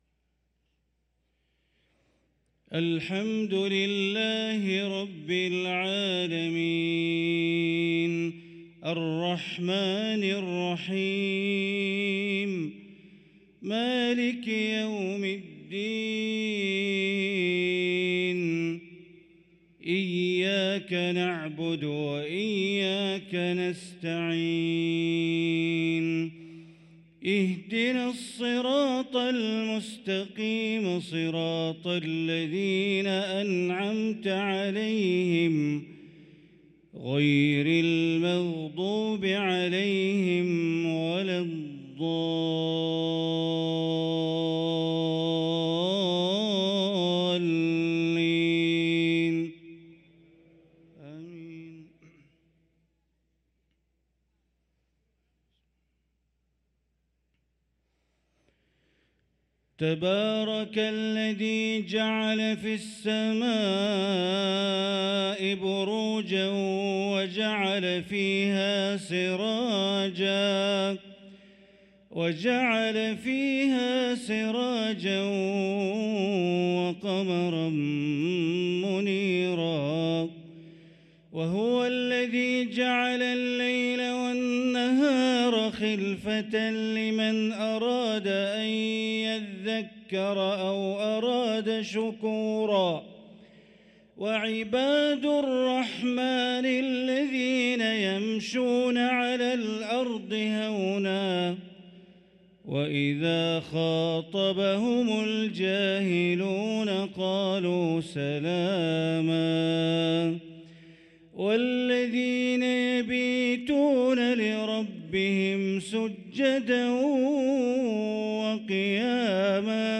صلاة العشاء للقارئ بندر بليلة 17 صفر 1445 هـ
تِلَاوَات الْحَرَمَيْن .